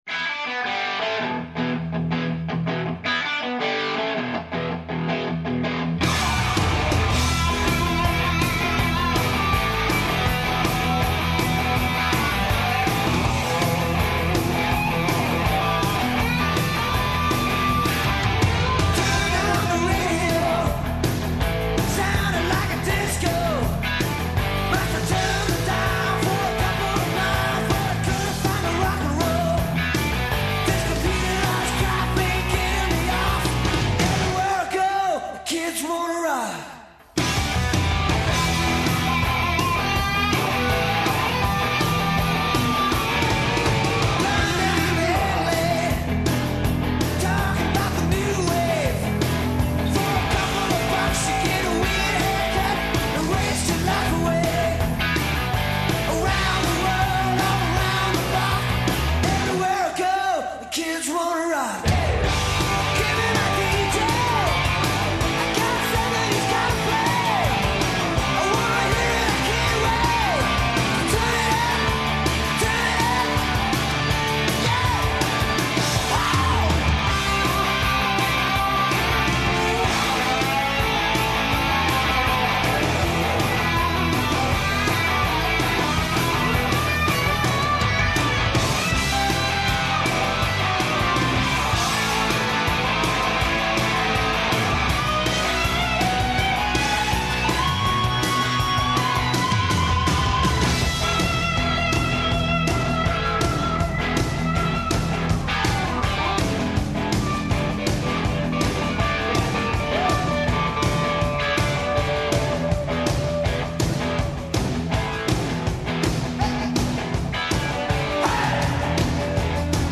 У данашњем Индексу упознаћете бенд СИВИ, који ће нам представити неке од својих песама и открити где ускоро наступају.